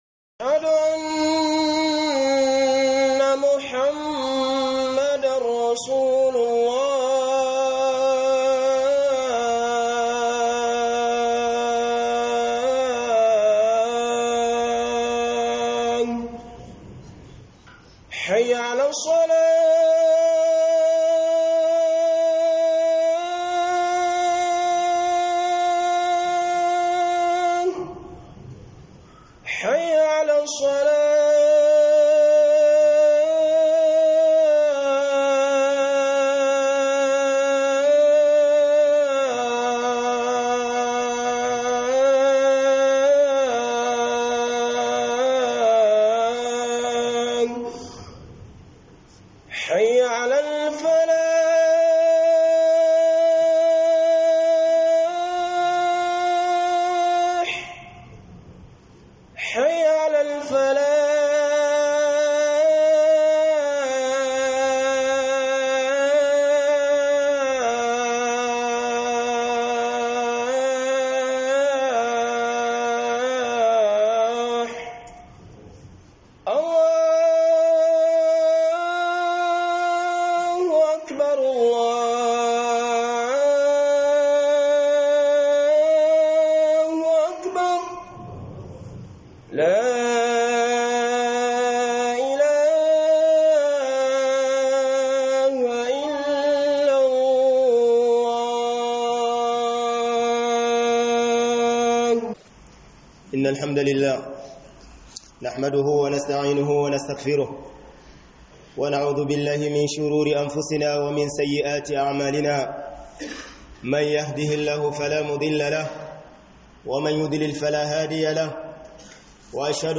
ALAMOMIN IN SIHIRI DA YANDA AKE LALATA SHI - Huduba